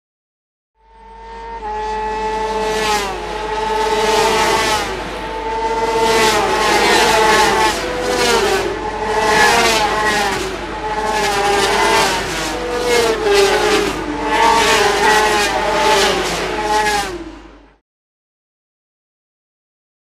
Motorcycles; By; 500 Cc Motorbikes Past Fast In Close Succession. Spectators Voices Breaking Through Now And Again.